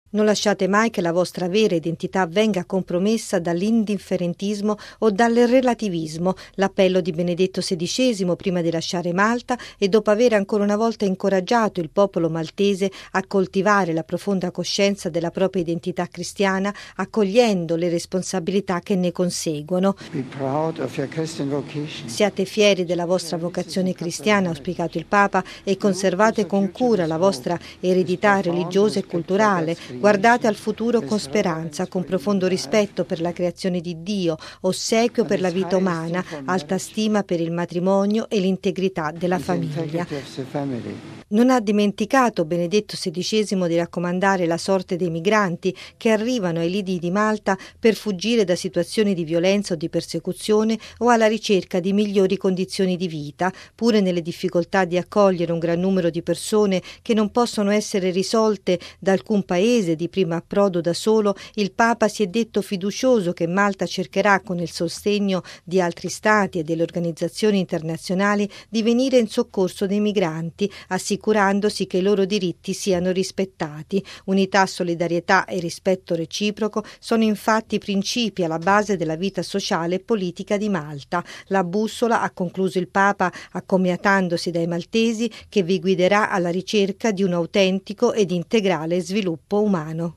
◊   Unità, solidarietà e rispetto reciproco: sono queste le caratteristiche alla base della vita sociale e politica del popolo maltese, che il Papa ha voluto rimarcare nel suo discorso di congedo, ieri sera all’aeroporto di Luka, alla presenza del capo di Stato George Abela.